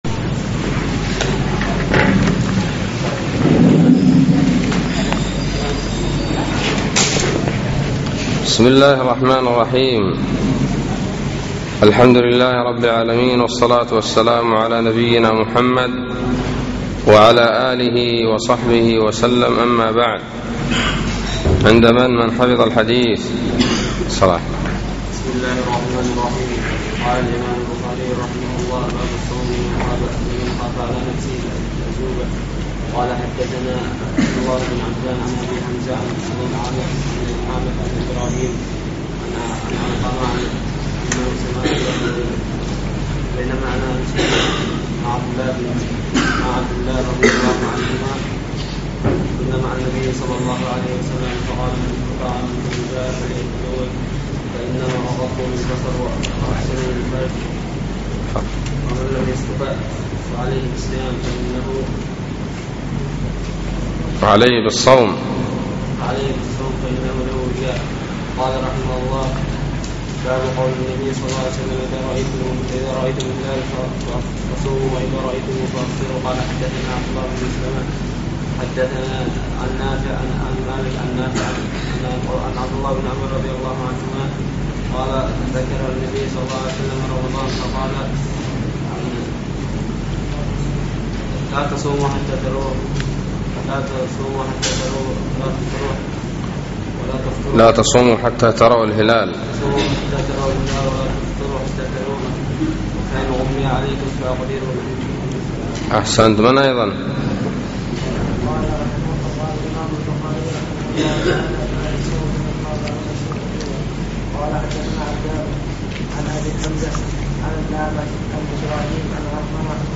الدرس التاسع من كتاب الصوم من صحيح الإمام البخاري